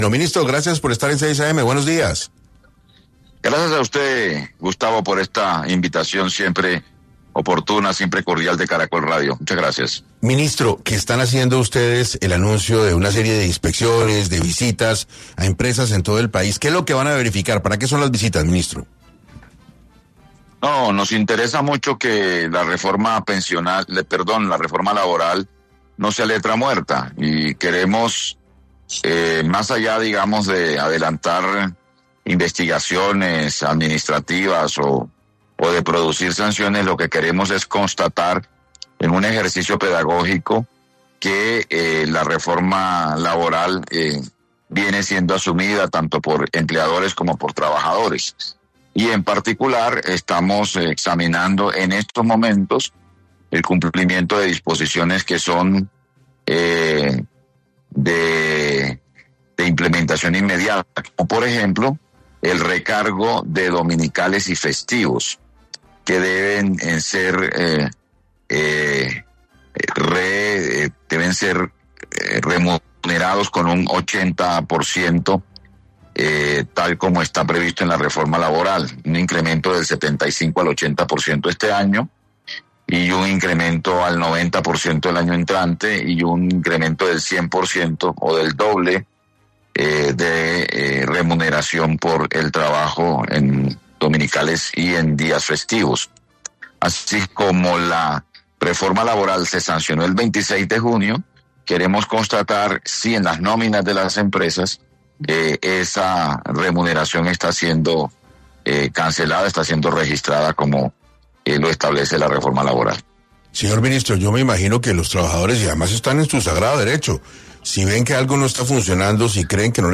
El ministro de Trabajo, habló en 6AM de Caracol Radio sobre los detalles y las quejas de la reforma laboral.